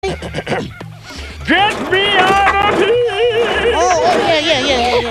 PLAY i gotta get out of here meme